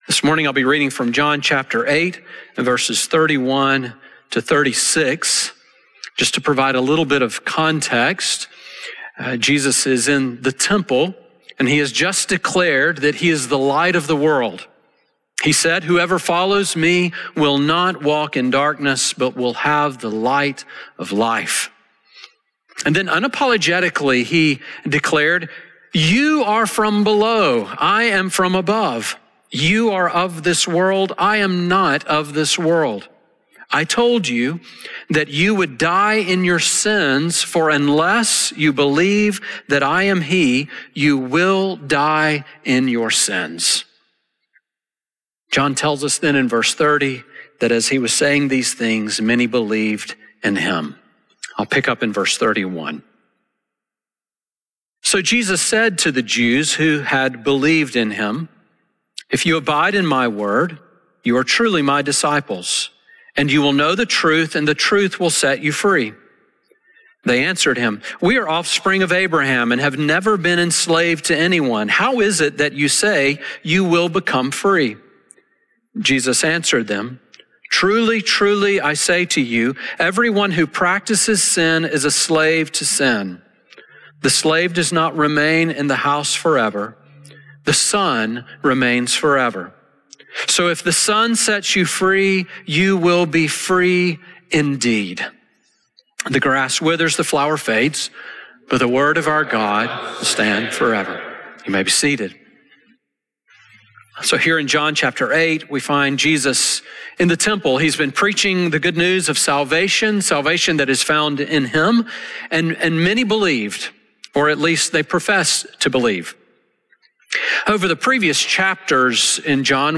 Sermon audio from Riverwood Presbyterian Church in Tuscaloosa, Alabama.